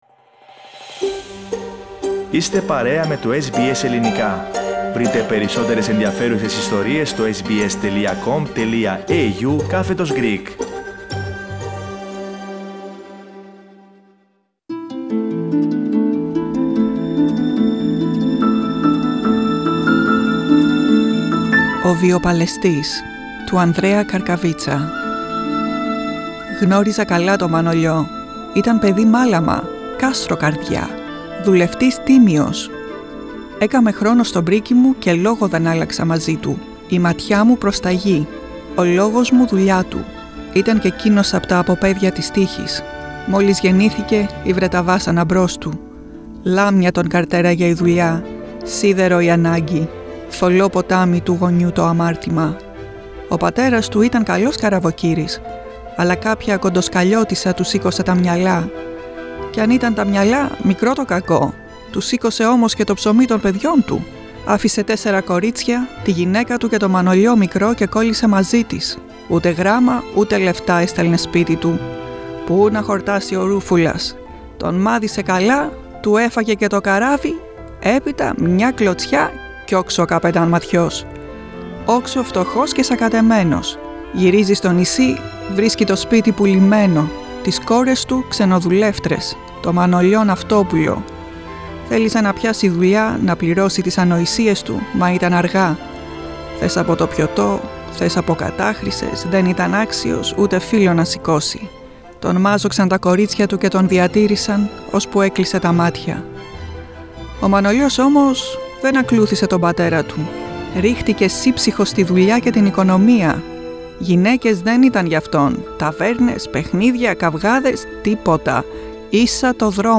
Listen to a short story by Andreas Karkavitsas about a young man who fulfilled his duty to his family.